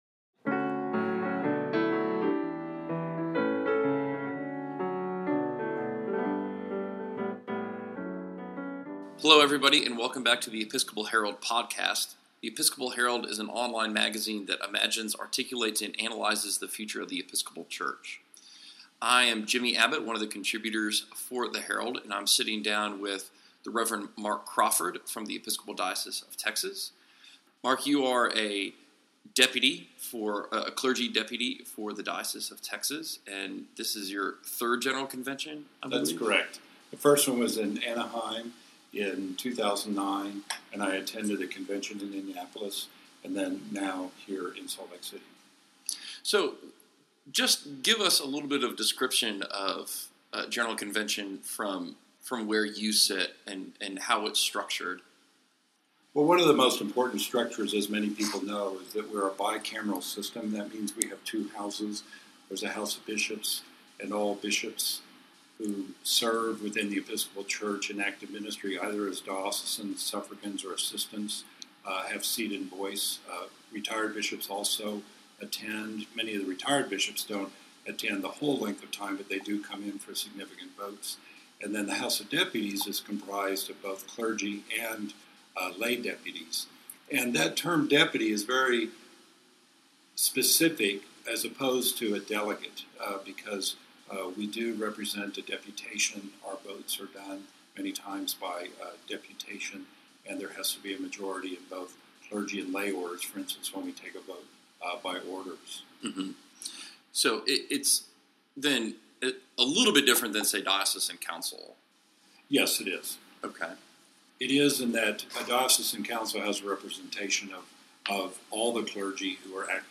Episcopal Herald Podcast – Conversation Series